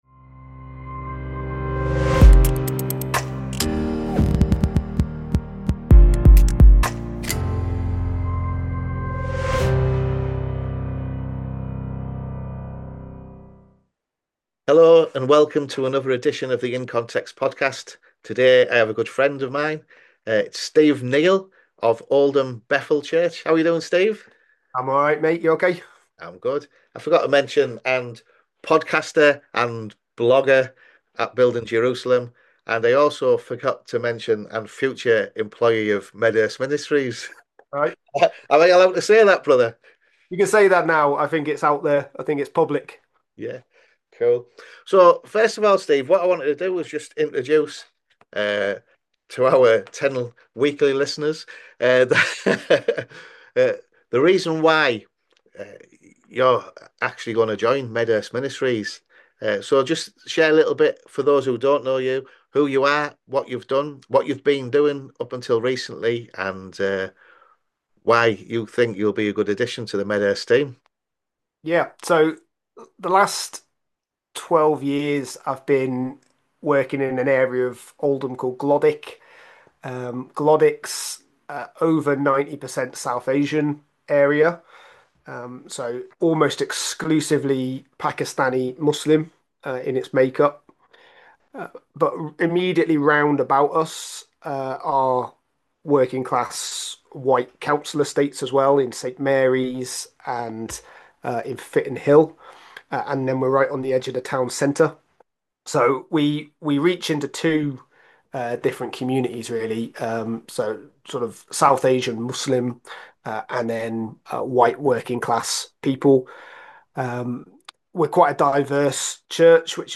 Download - S1 Ep75: #71: Navigating Cultural Change. An interview